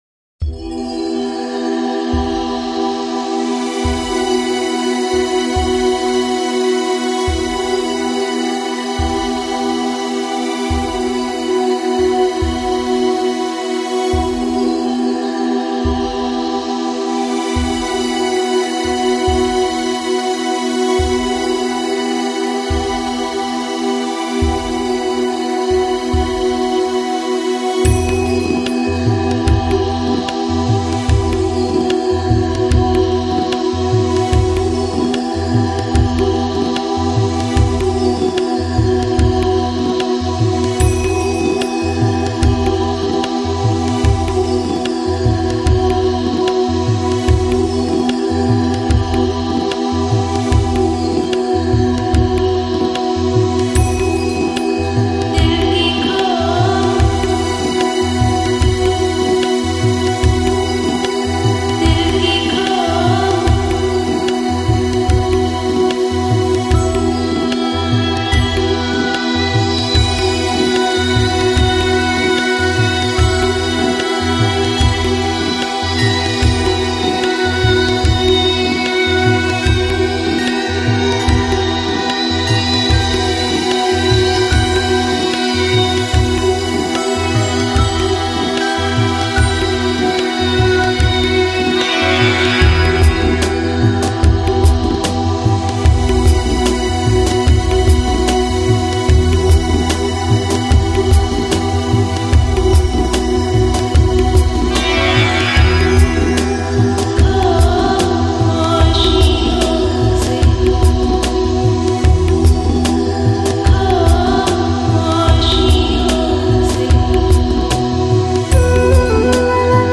Lounge, Lo-Fi, Ambient, New Age, Chill Out Носитель